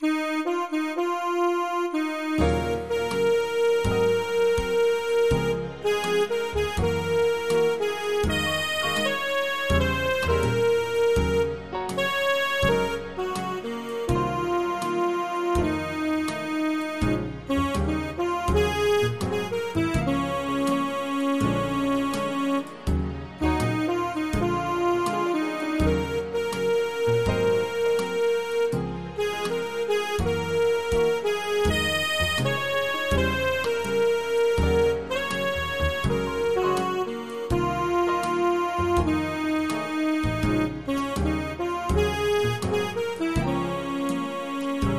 midi/karaoke